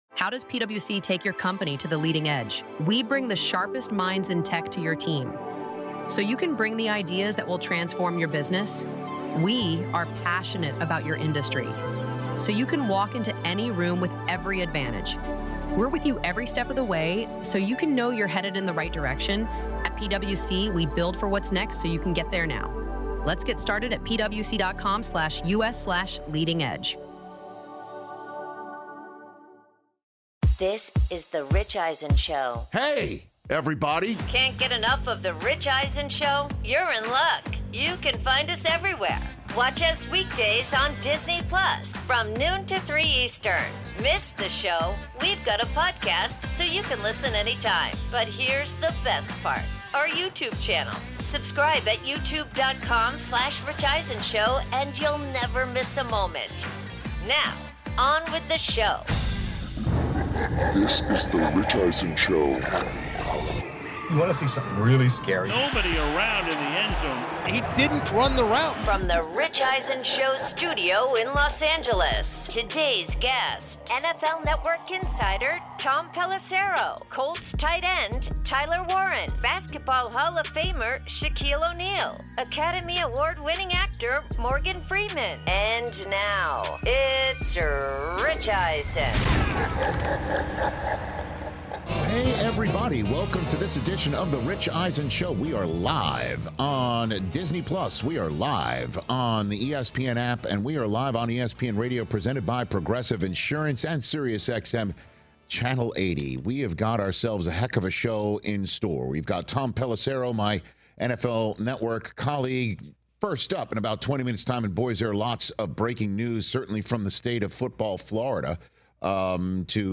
Rich weighs in the Miami Dolphins firing GM Chris Grier following their ugly Thursday Night Football loss to the Ravens and what it bodes for embattled head coach Mike McDaniel. NFL Insider Tom Pelissero and Rich discuss the Dolphins big shakeup and what could be coming next for the 2-8 team, how long the Jacksonville Jaguars could be without WR/CB Travis Hunter, says which underperforming teams (ahem Raiders, Jets and Saints) could be sellers at the trade deadline, and more.